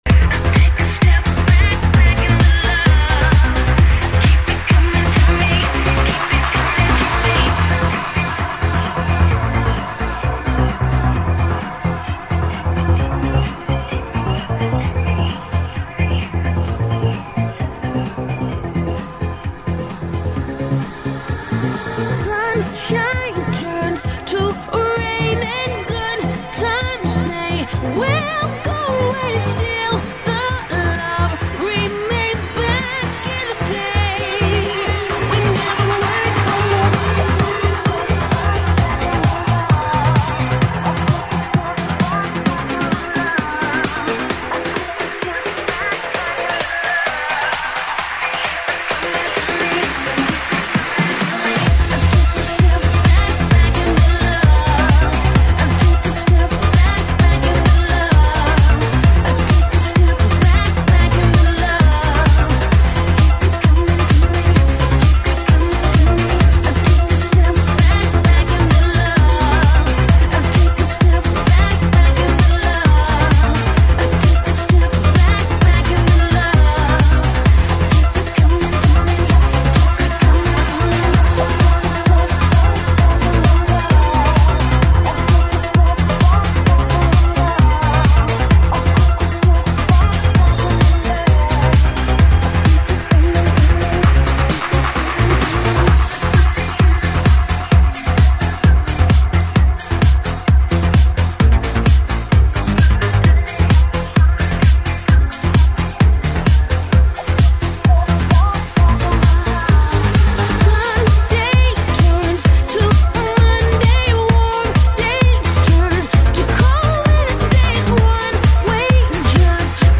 *** друзья очень классный (electro house) ***